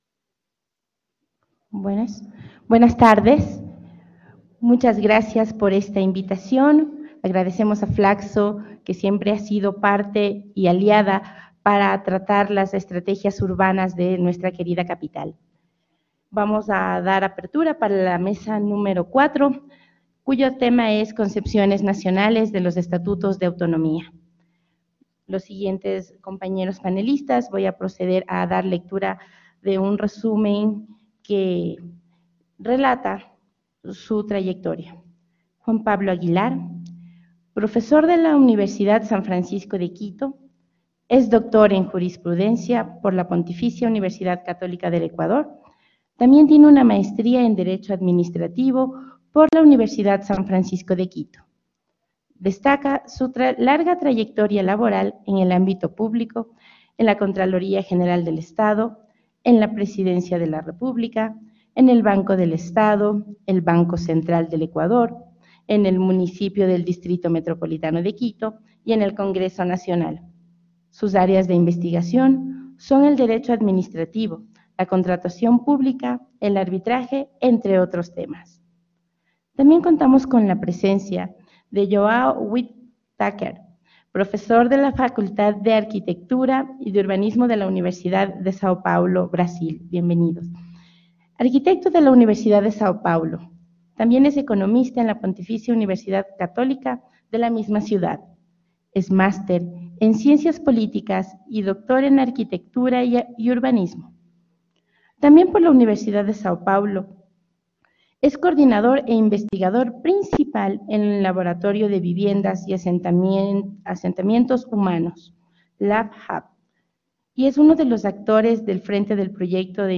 Seminario Internacional: Capitales latinoamericanas: autonomía y desarrollo, 22 y 23 de agosto de 2019. Hemiciclo FLACSO Ecuador.
Moderadora: Gisela Chalá, vicealcaldesa del MDMQ.